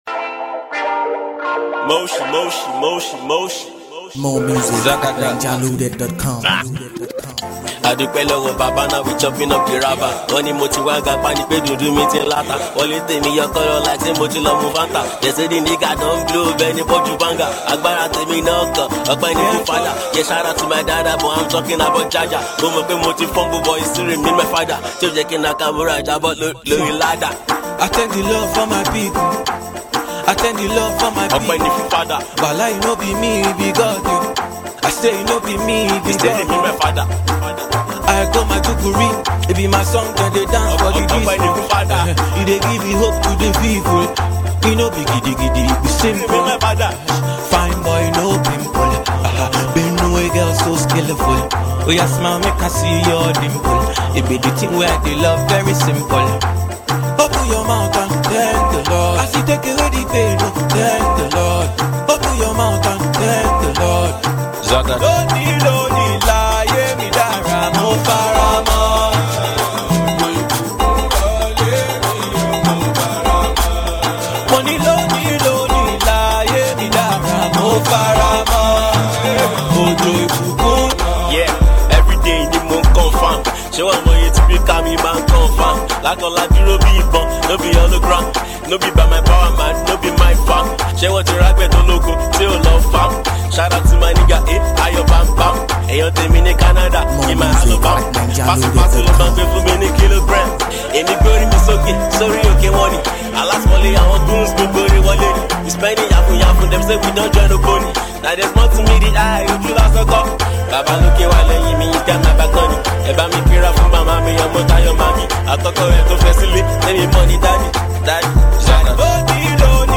smooth
Africanmusic